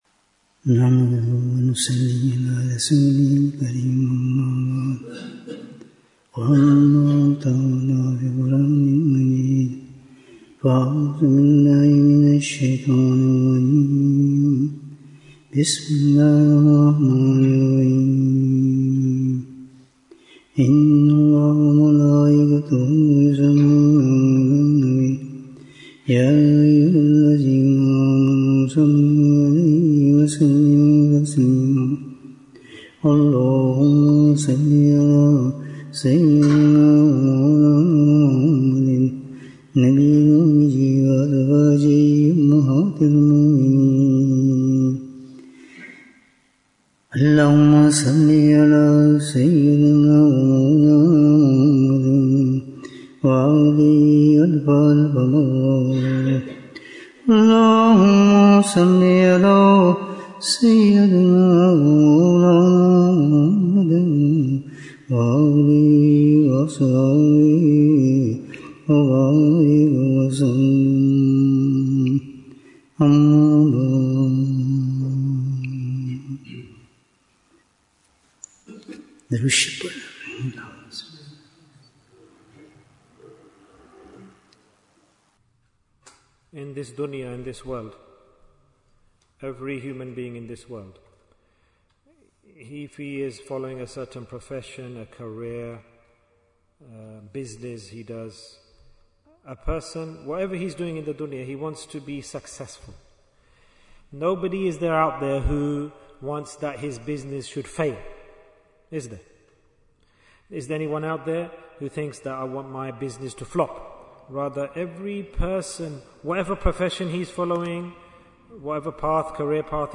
Bayan, 59 minutes7th August, 2025